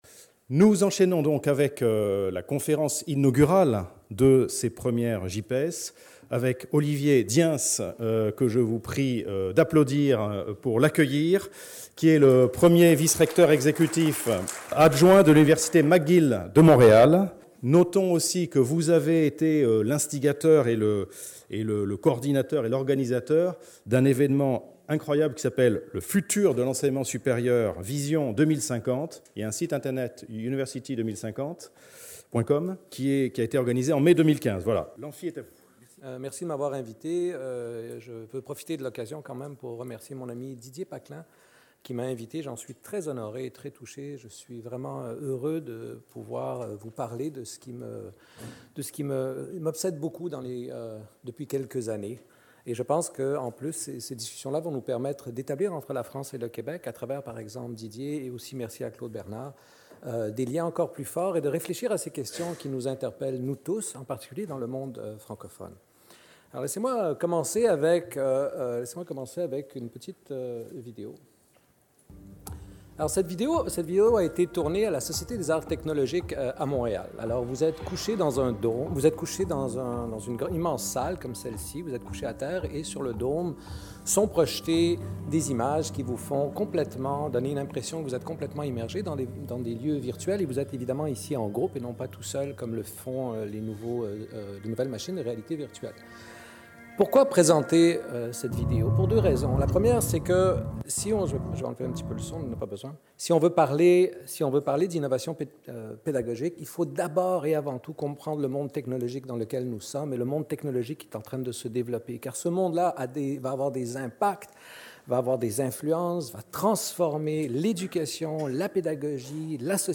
JIPES 2016 // Conférence inaugurale : L’innovation pédagogique dans l'enseignement supérieur : entre opportunité et nécessité | Canal U